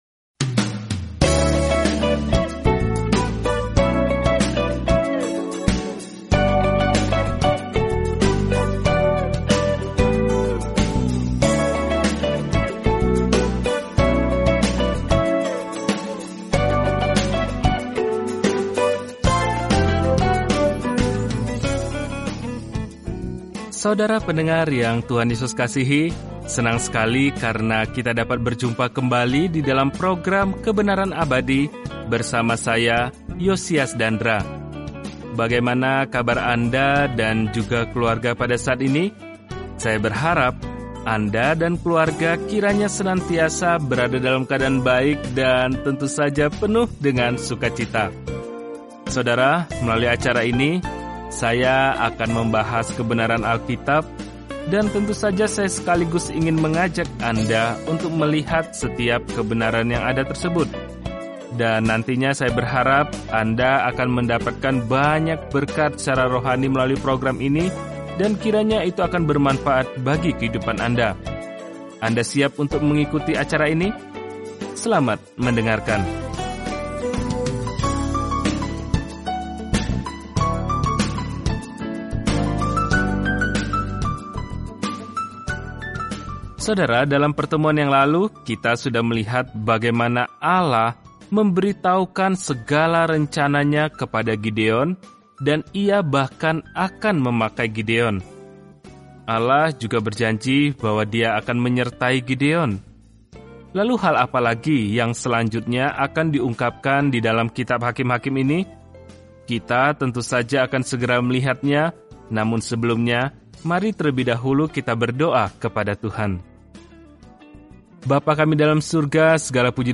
Firman Tuhan, Alkitab Hakim-hakim 6:23-40 Hakim-hakim 7:1-15 Hari 4 Mulai Rencana ini Hari 6 Tentang Rencana ini Hakim-hakim mencatat kehidupan orang-orang yang terkadang berbelit-belit dan terbalik saat menjalani kehidupan baru di Israel. Perjalanan harian melalui Hakim-hakim saat Anda mendengarkan studi audio dan membaca ayat-ayat tertentu dari firman Tuhan.